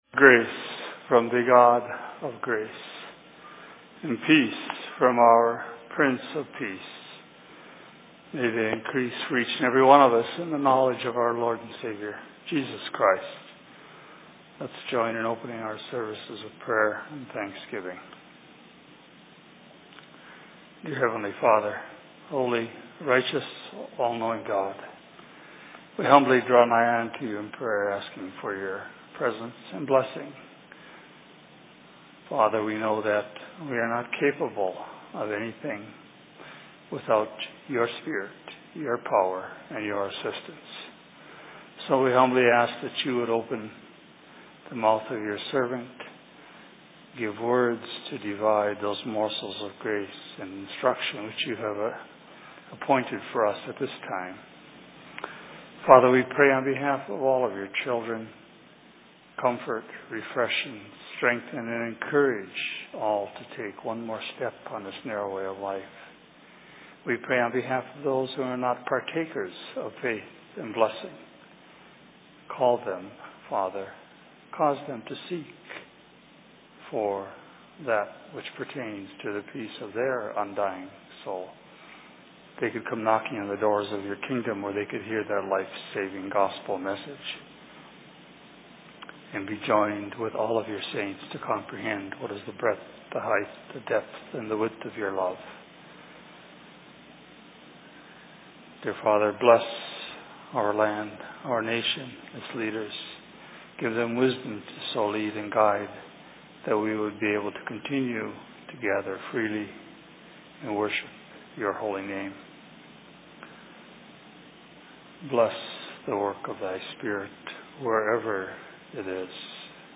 Sermon in Minneapolis 13.08.2017